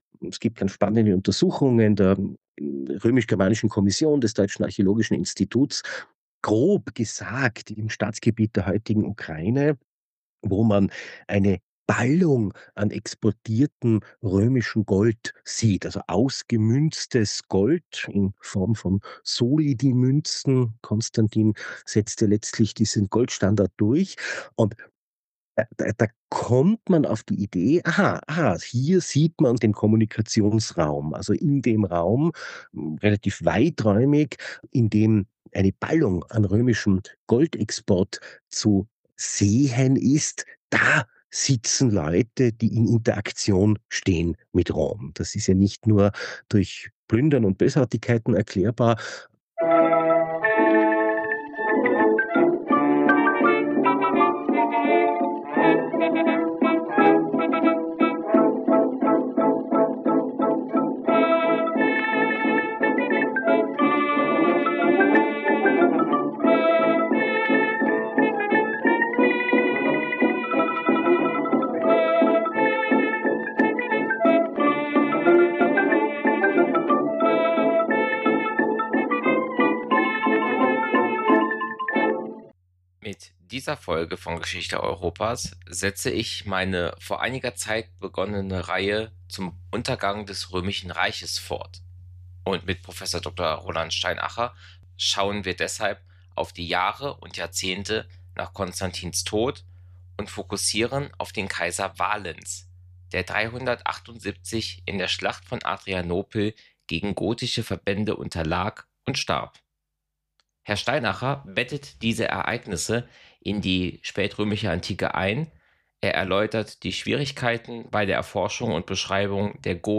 Ein Podcast über die Geschichte Europas. Gespräche mit Expert:innen, angefangen beim geologischen Entstehen der europäischen Halbinsel bis hin zum heutigen Tag.